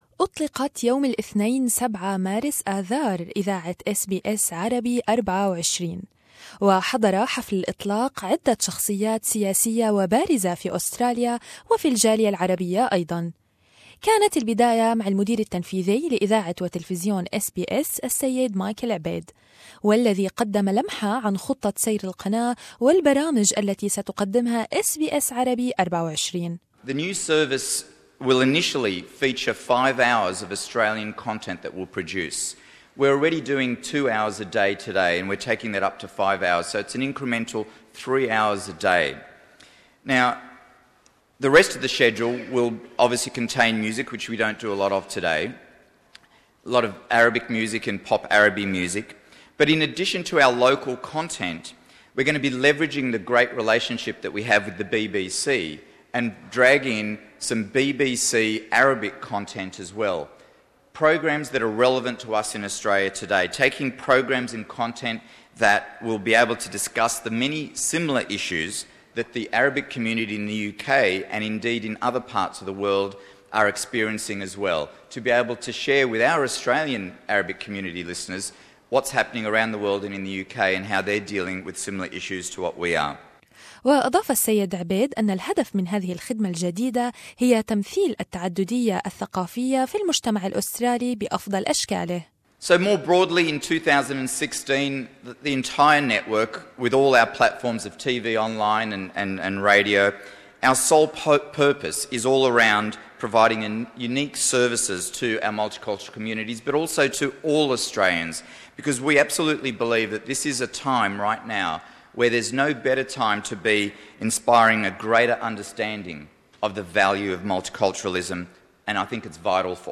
تسجيل: مقتطفات من ما قاله سياسيون عن اطلاق SBS Arabic24